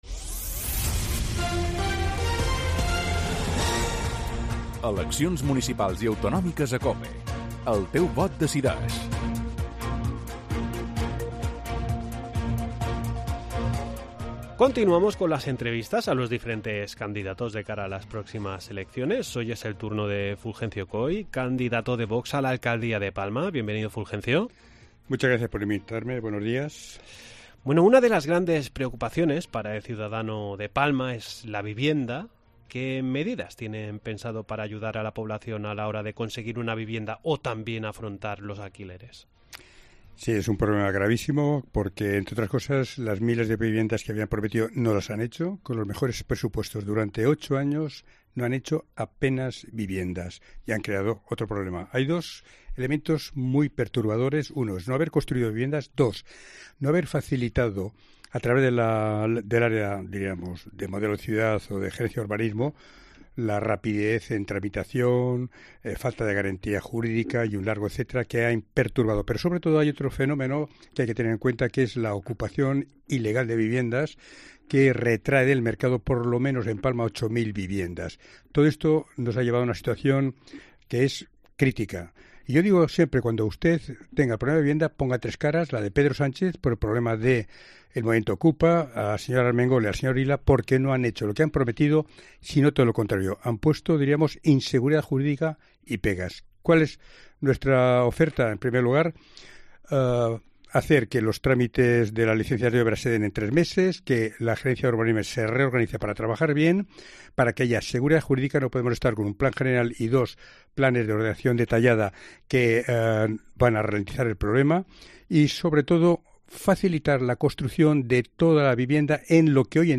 AUDIO: Continuamos con las entrevistas a los diferentes candidatos de cara a las próximas elecciones.